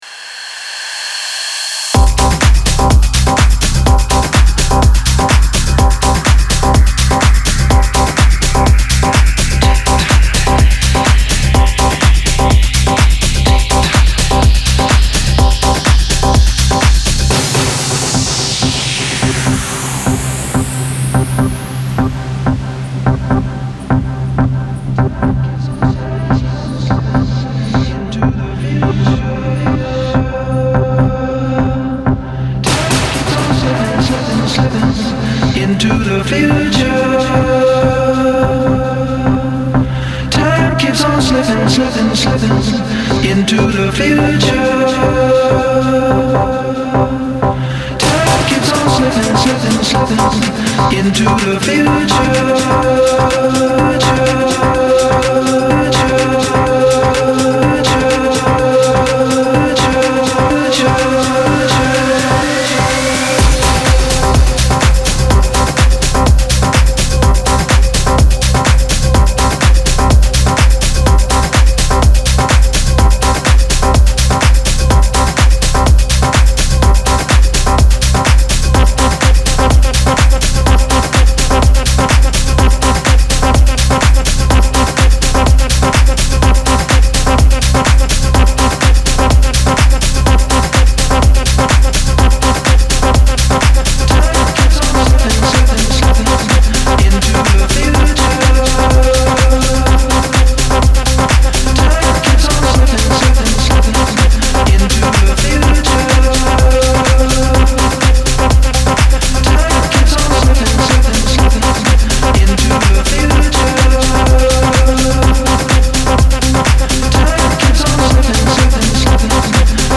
Tech House Radio